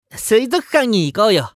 青年ボイス～シチュエーションボイス～